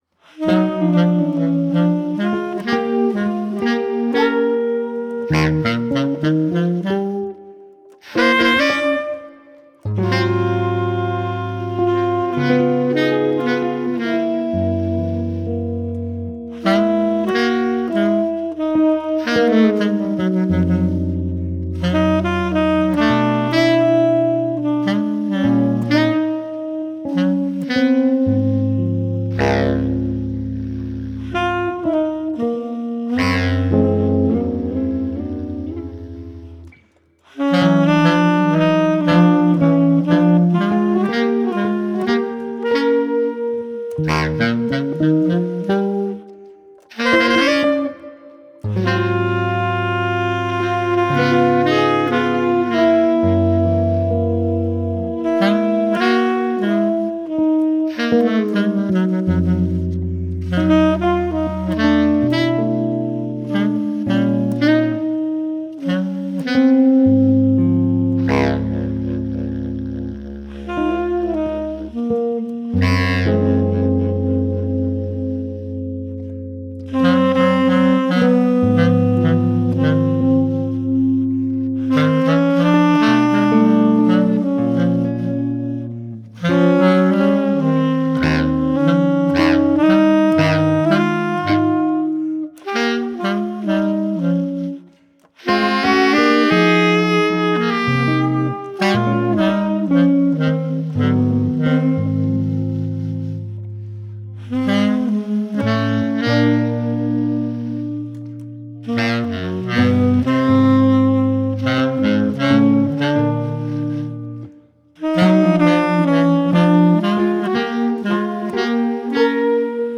guitar
saxophone
b. clarinet